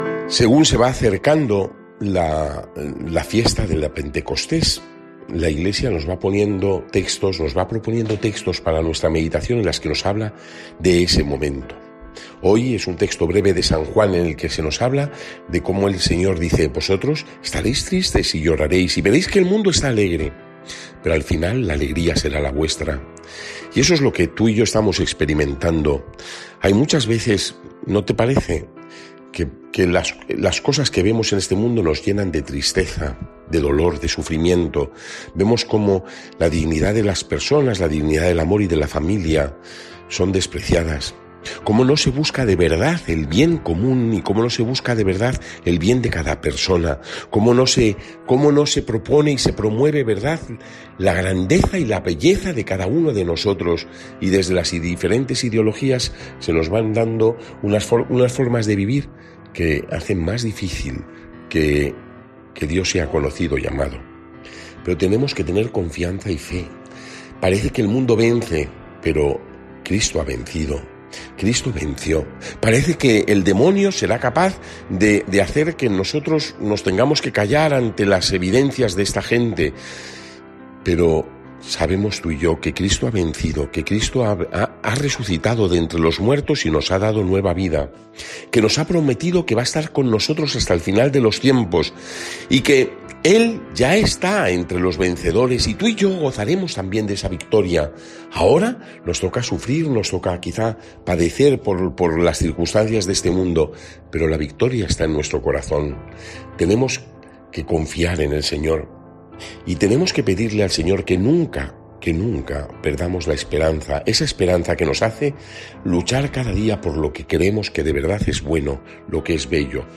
Evangelio del día
Lectura del santo evangelio según san Juan 16, 16-20